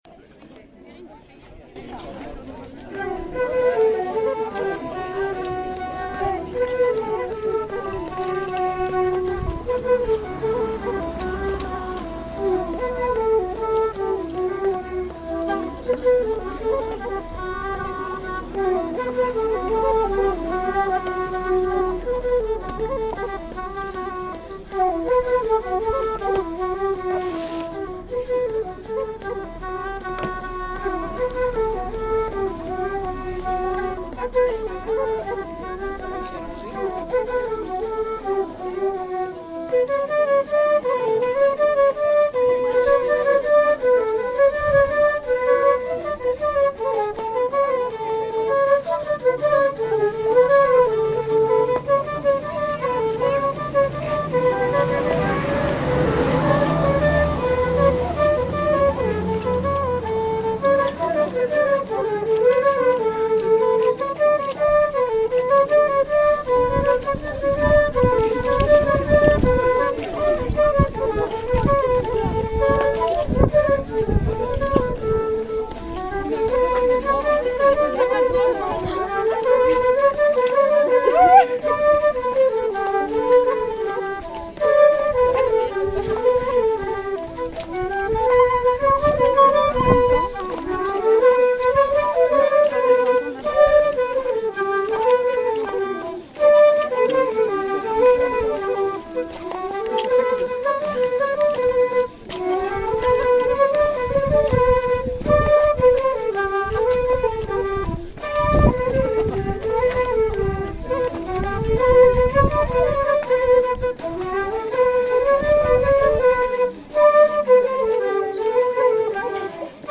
03_plinn-flutes.mp3